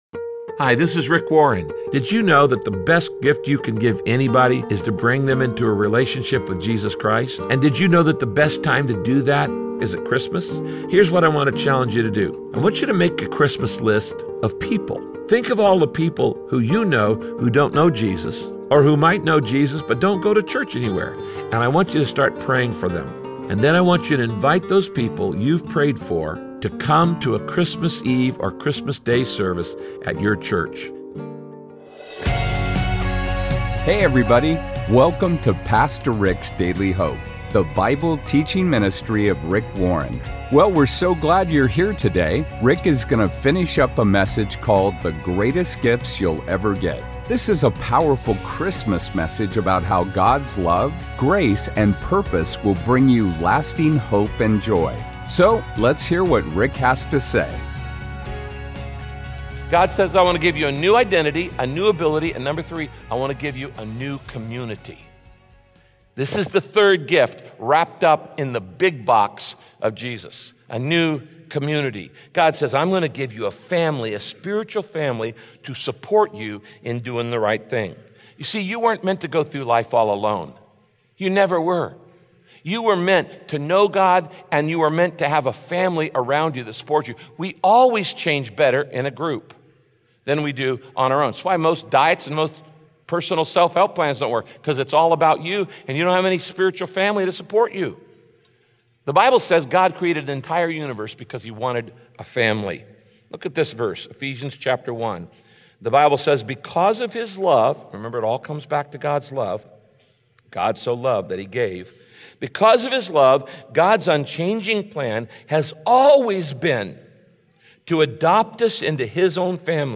In this message, Pastor Rick explains that God offers you many gifts through Jesus.